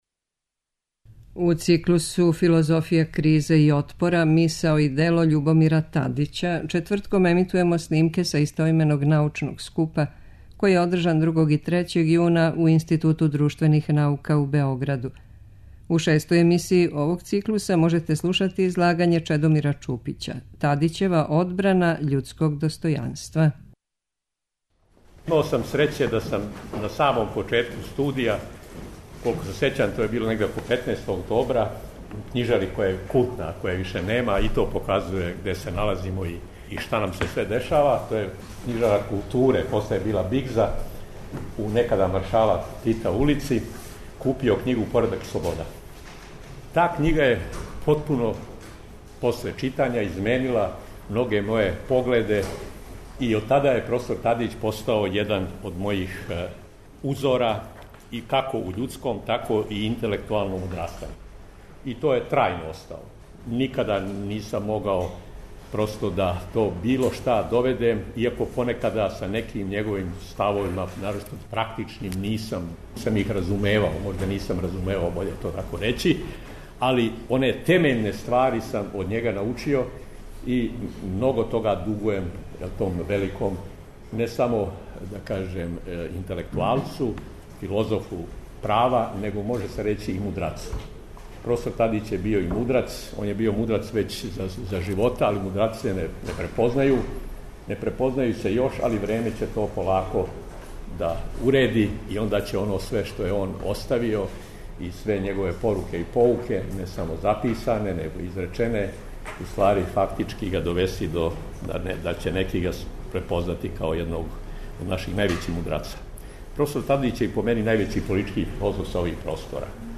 Научни скупoви